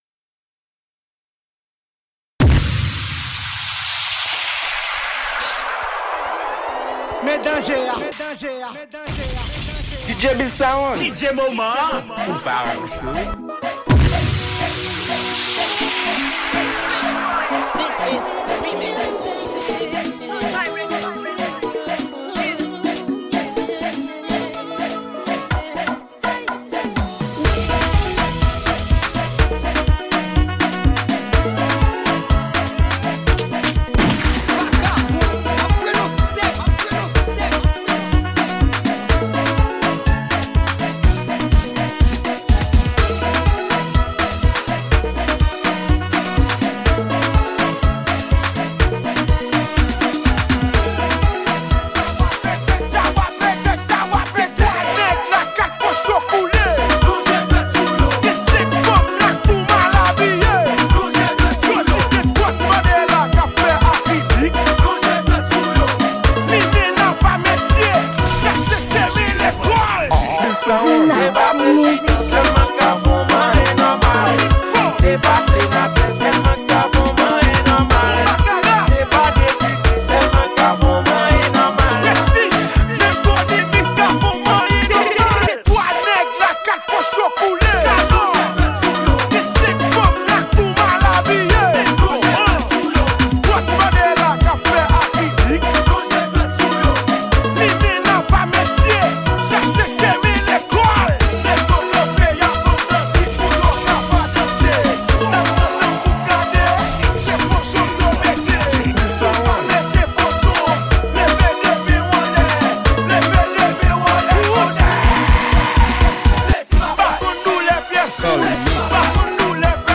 Genre: Dj.